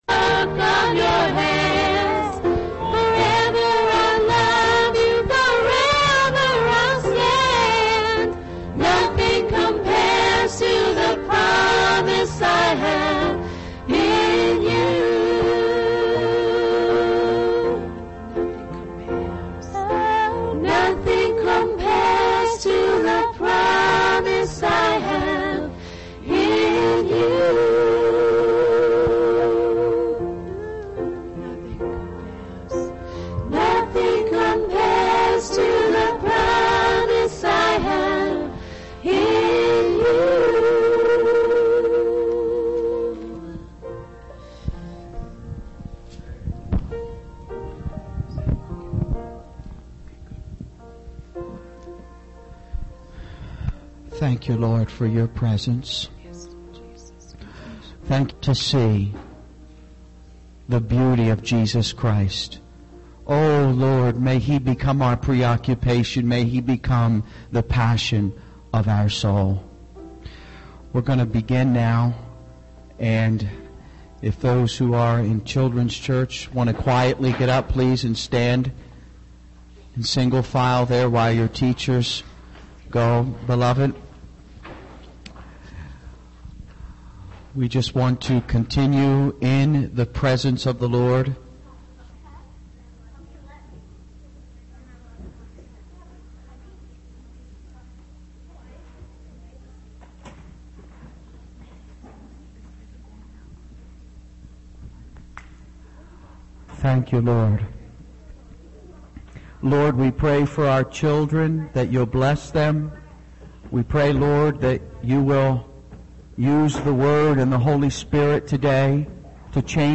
The sermon calls for a deep introspection and a commitment to prayer, particularly for the next generation, to cultivate a home environment filled with God's love and unity.